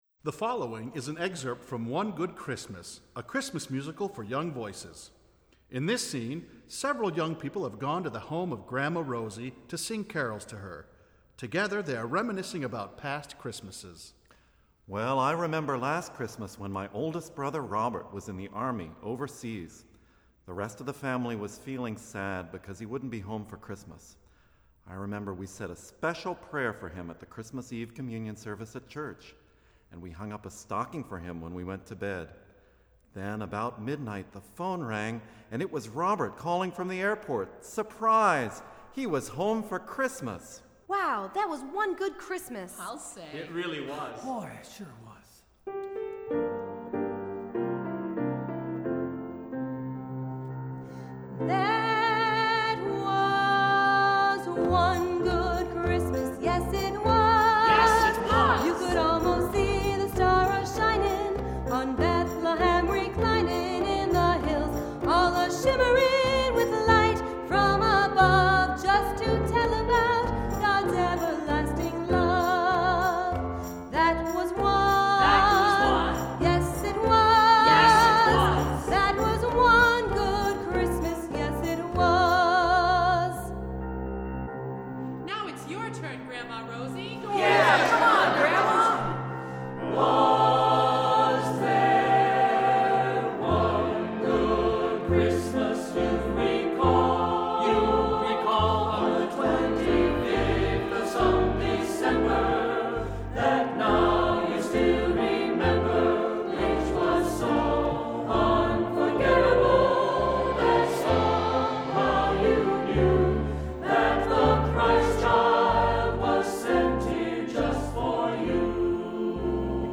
Voicing: Unison; Two-part equal; Two-part mixed; SATB